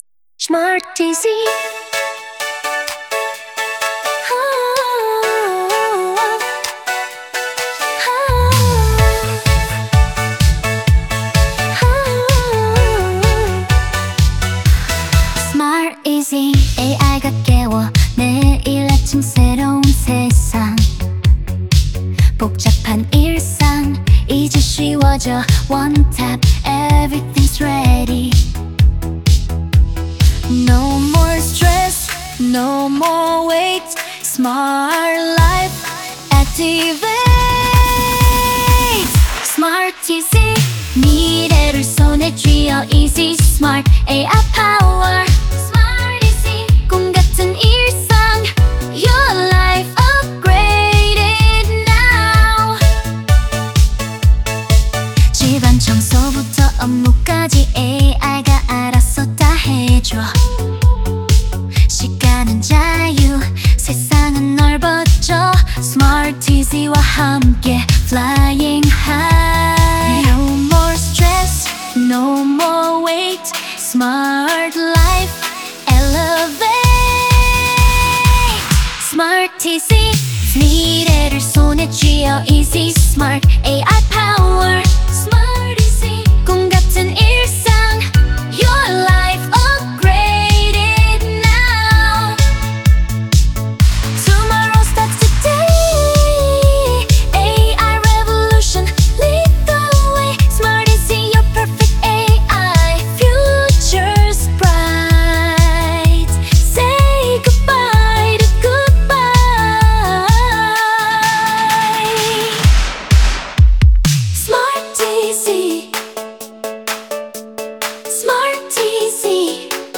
corporate pop, synthwave fusion, futuristic electronic, 128 BPM, energetic major key, clean modern production, driving synth bass, arpeggiated leads, crisp hi-hats, uplifting pads, subtle vocal chops, rising synths intro, synth glow fade outro, logo sound sting end, professional commercial mix, tech